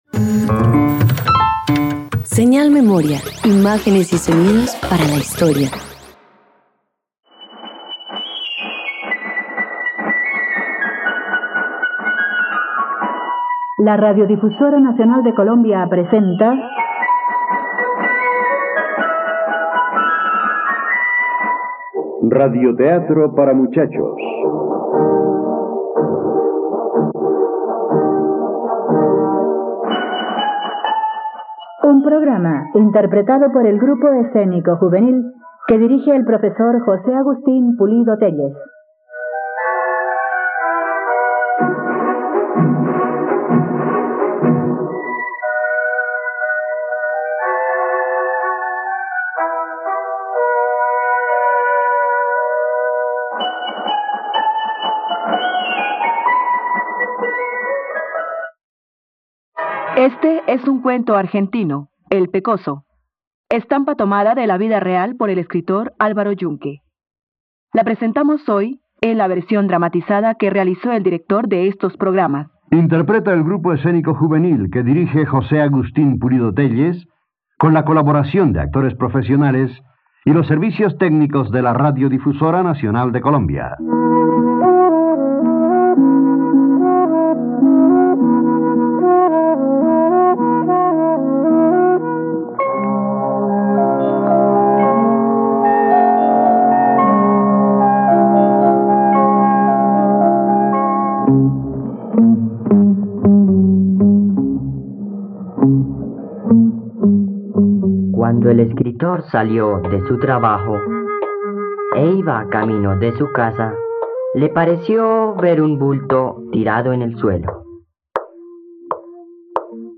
El pecoso - Radioteatro dominical | RTVCPlay
radioteatro